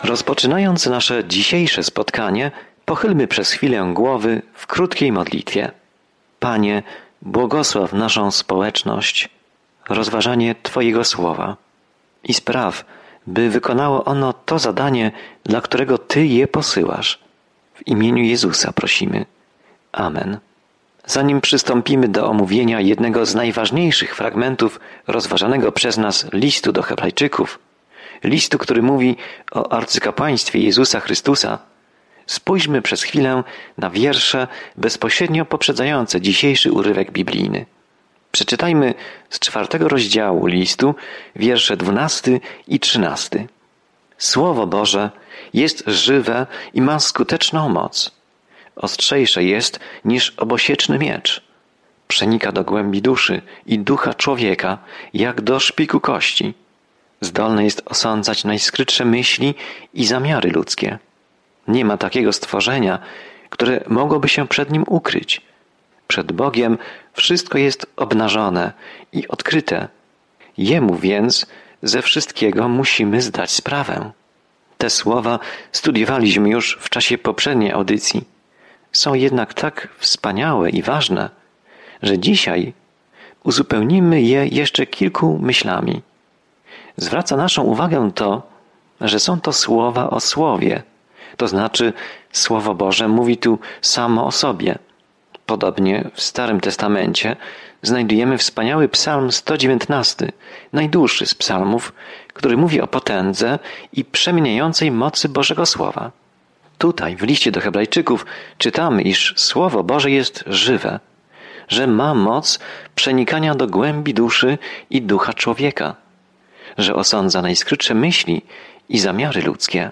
Codziennie podróżuj po Liście do Hebrajczyków, słuchając studium audio i czytając wybrane wersety słowa Bożego.